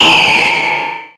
Cries
ALTARIA_1.ogg